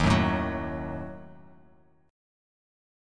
D#dim chord